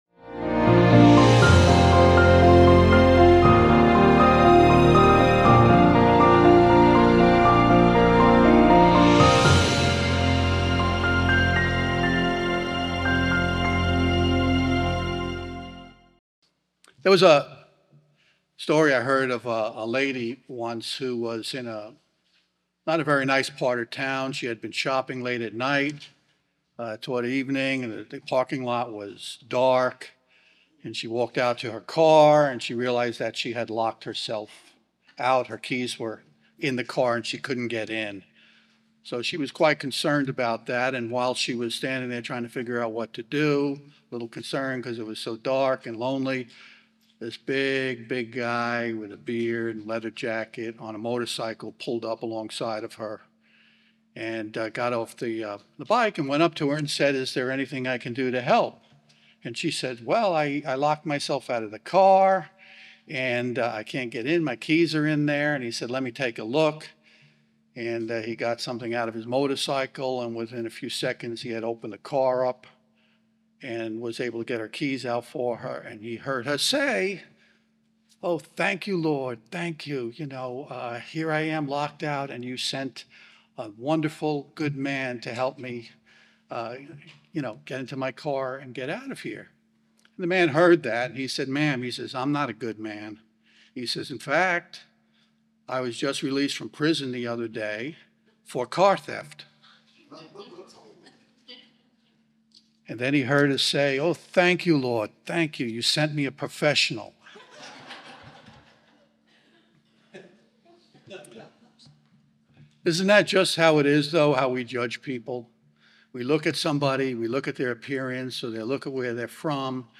Sermons
Given in Charlotte, NC